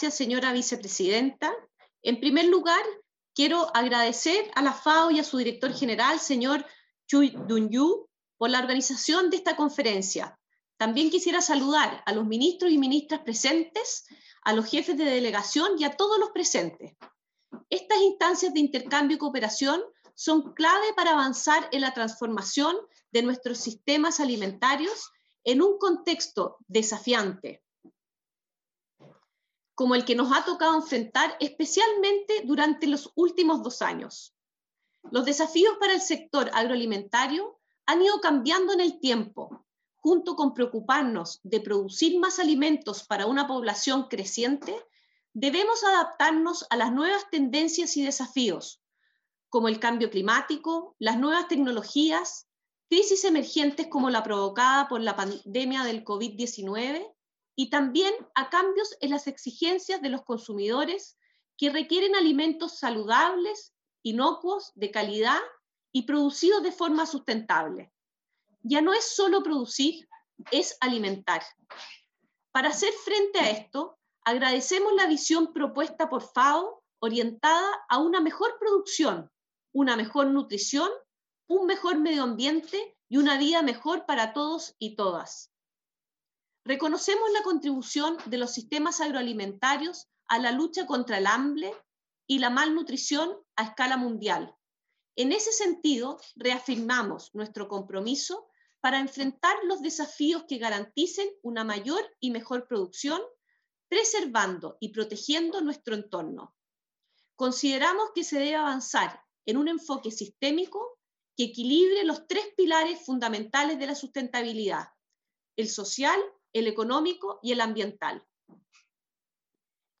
GENERAL DEBATE
Addresses and Statements
Excma. Sra. Doña Maria Emilia Undurraga, Ministra de Agricultura de la República de Chile
(Plenary – Español)